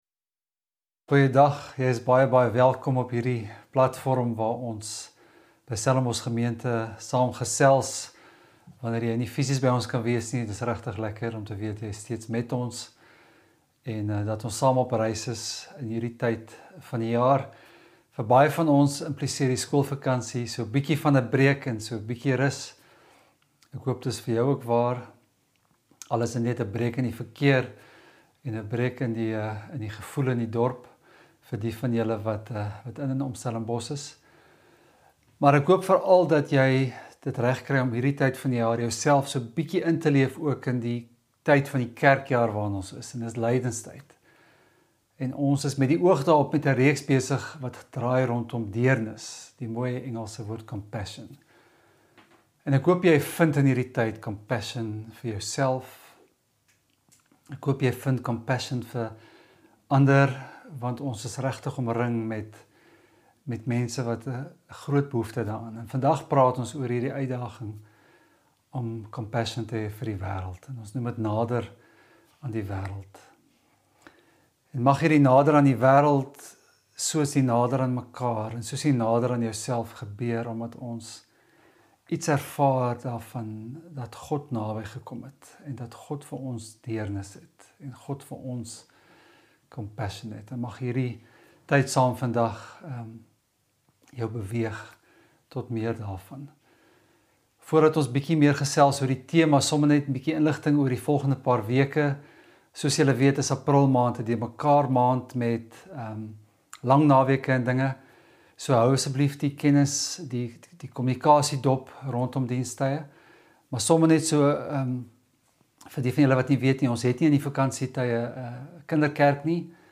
Stellenbosch Gemeente Preke